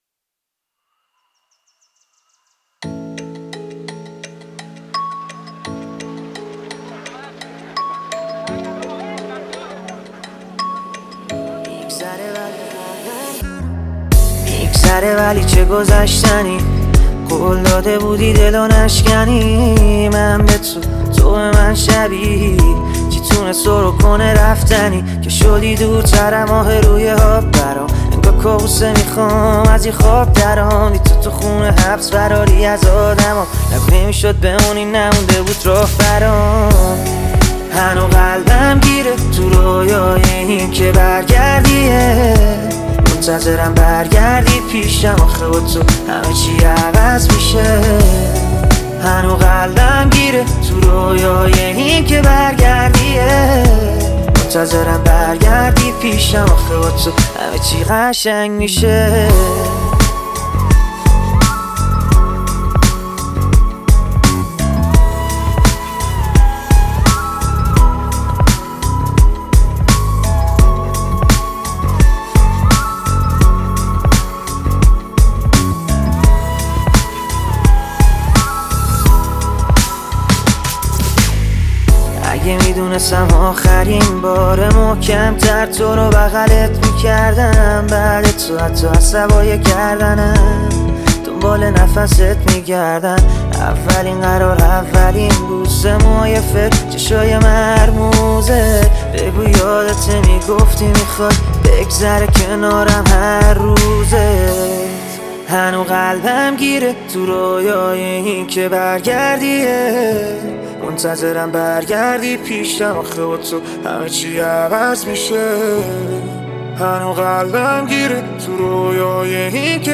خواننده جوان گراشی